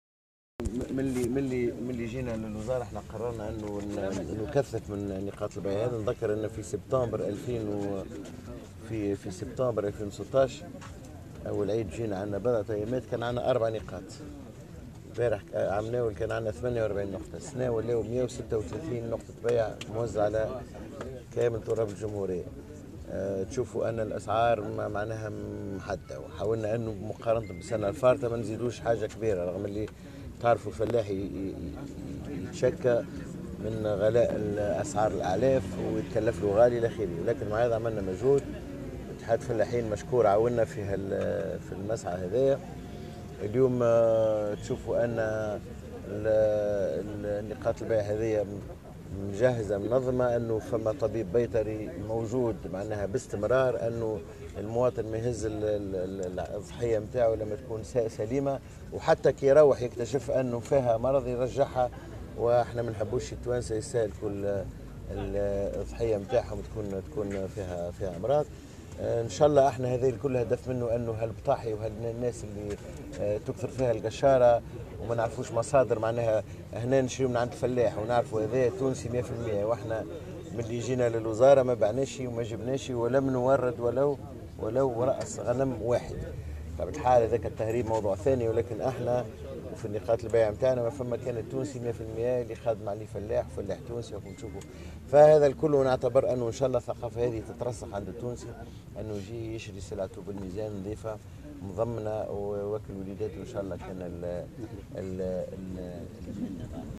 وأضاف في تصريح لمراسلة "الجوهرة أف أم" أنه منذ توليه وزارة الفلاحة قرر تكثيف نقاط بيع الأضاحي، مشيرا إلى وجود بيطري عند كل نقطة بيع لمراقبتها.